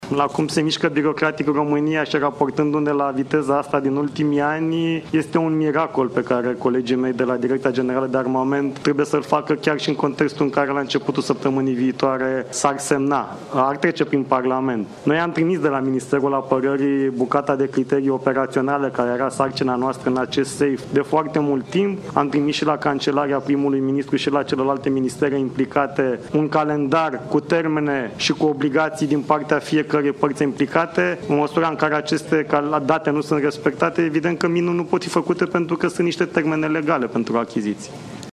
Banii din PNRR și programul SAFE sunt printre cele mai importante obiective în prezent, a declarat ministrul Apărării, în ultima zi a exercițiului NATO „Eastern Phoenix”, din Poligonul Capu Midia, județul Constanța.
Ministrul Apărării, Radu Miruță: „Este un miracol pe care colegii mei de la Direcția Generală de Armament trebuie să-l facă”